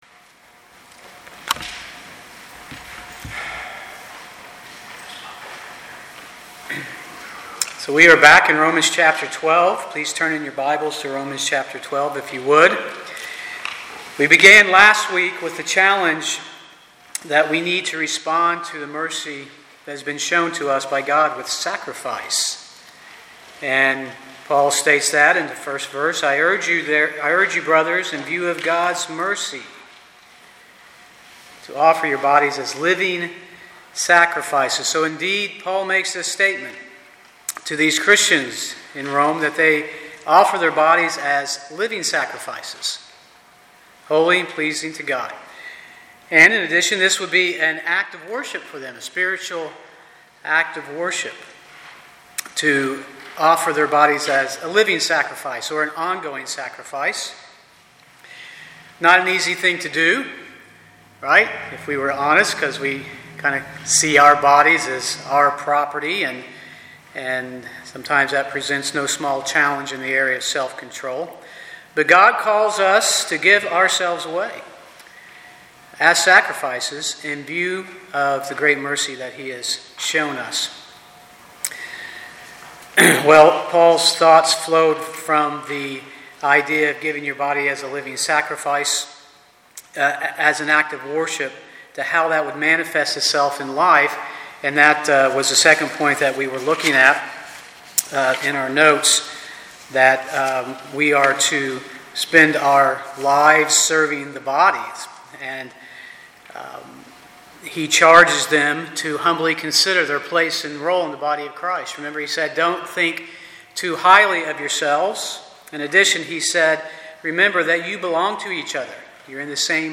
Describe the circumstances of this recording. Passage: Romans 12:1-21 Service Type: Sunday morning « Mercy and Sacrifice